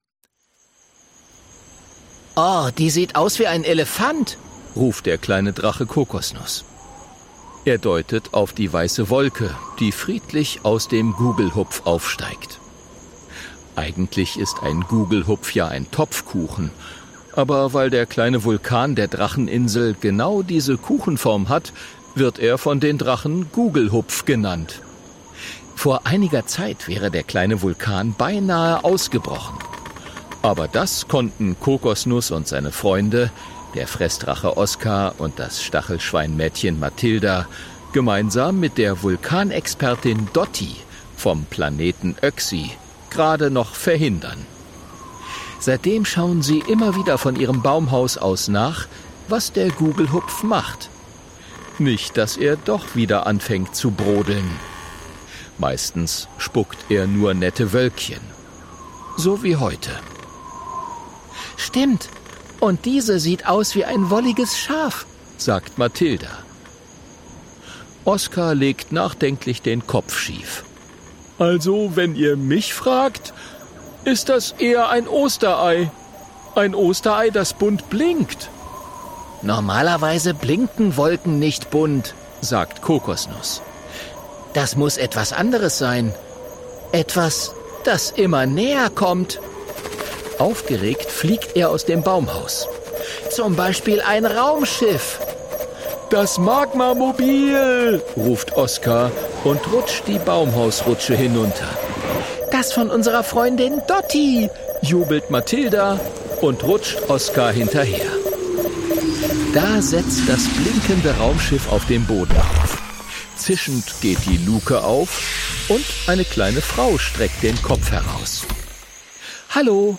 Hörbuch: Alles klar!